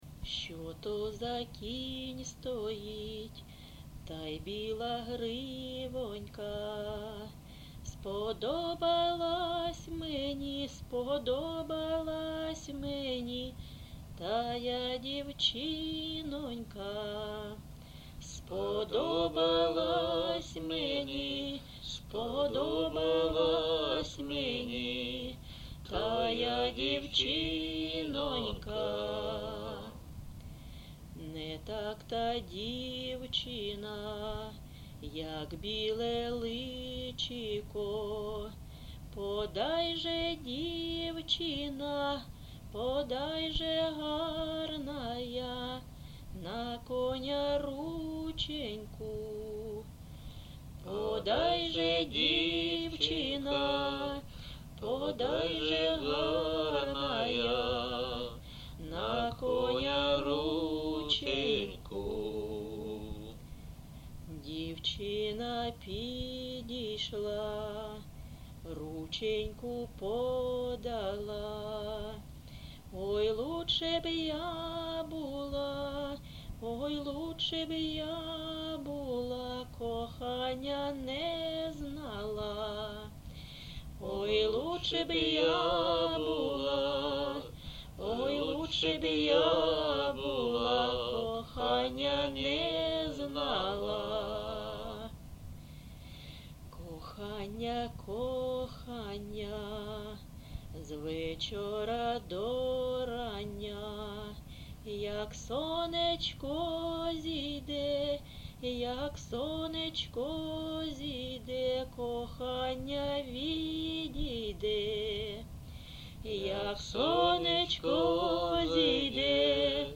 ЖанрПісні з особистого та родинного життя
Місце записум. Ровеньки, Ровеньківський район, Луганська обл., Україна, Слобожанщина